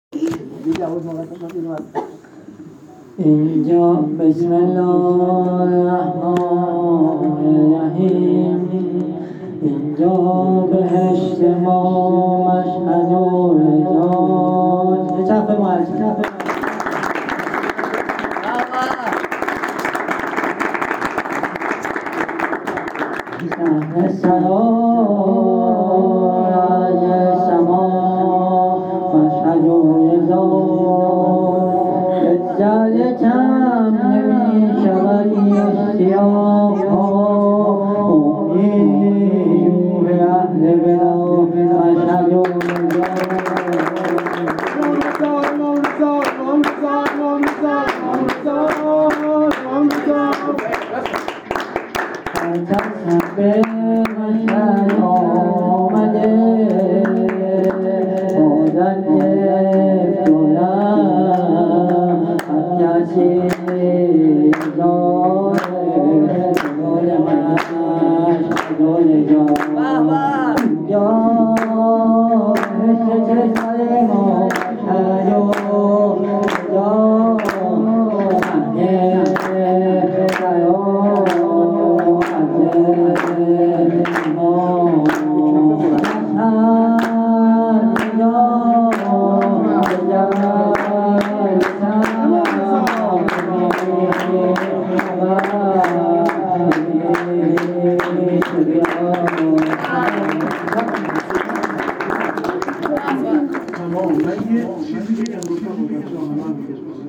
مدح و سرود امام رضا
مداح اهل بیت
هیت روضه الزهرا تهران